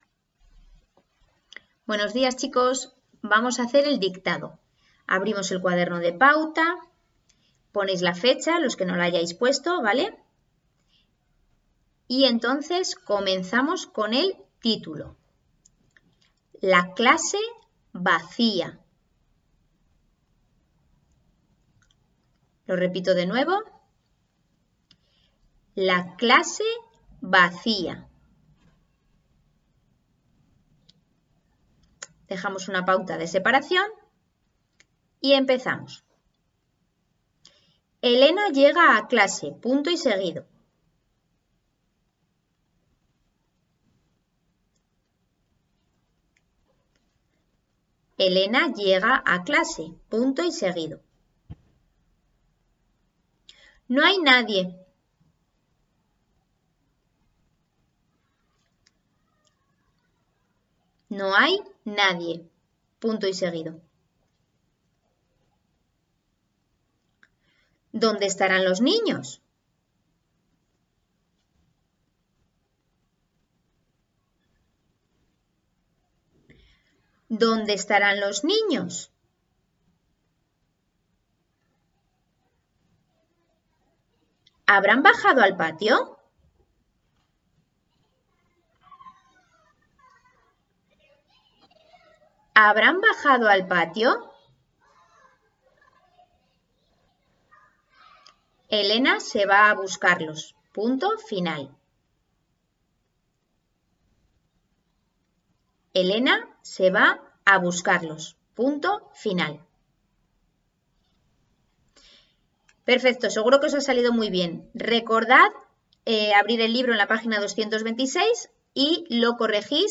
Dictado_pagina_226.mp3